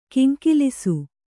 ♪ kiŋkilisu